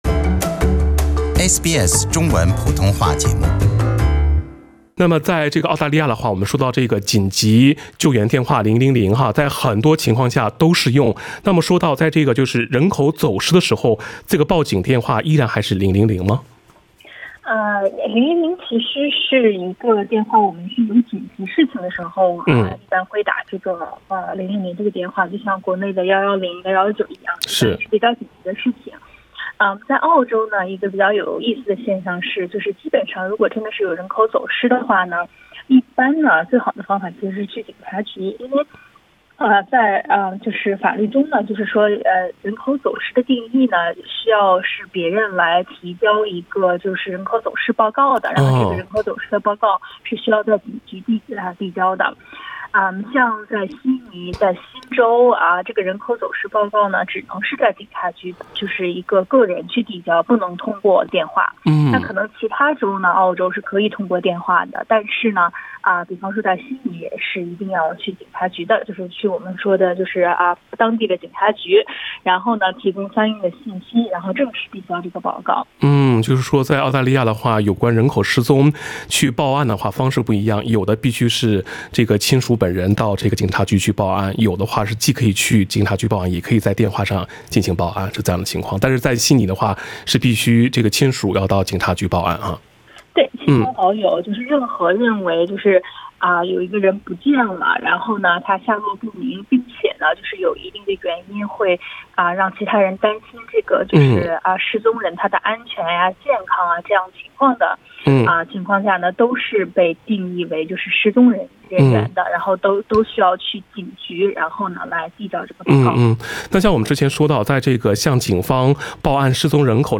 《现场说法》听众热线节目逢周二上午8点30分至9点播出。